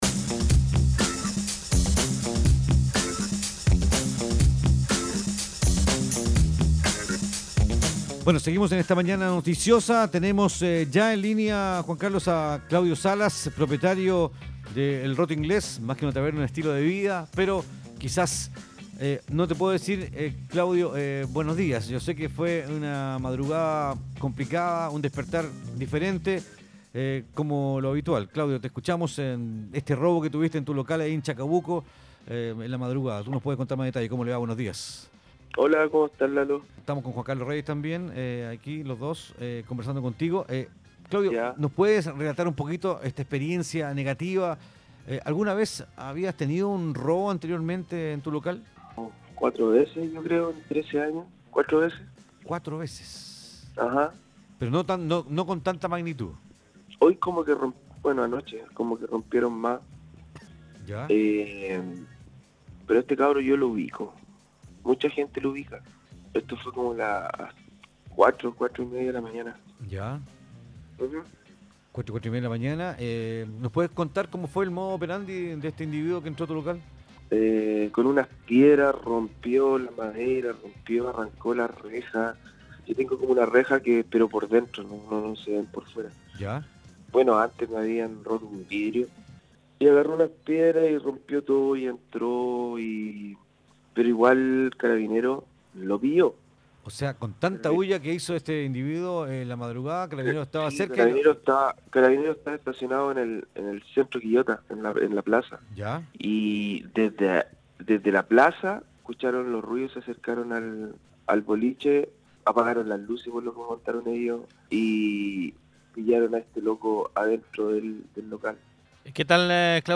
En conversación con Radio Quillota